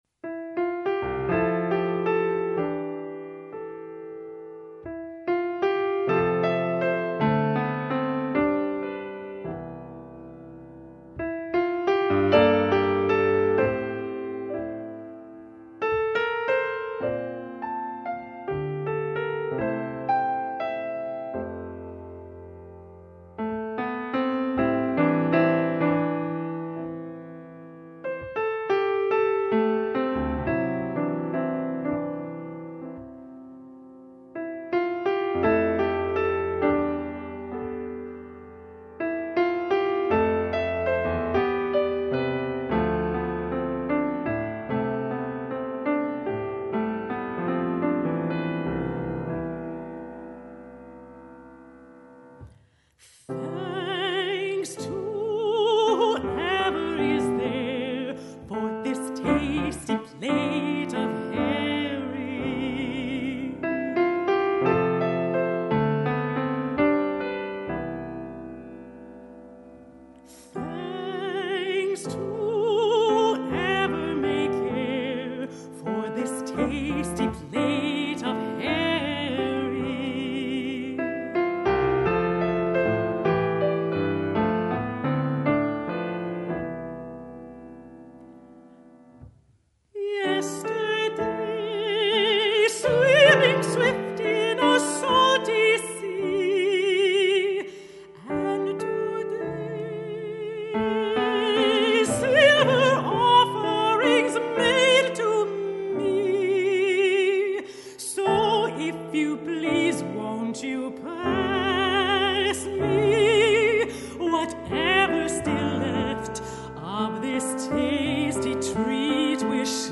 Vocal Language Collections, American-English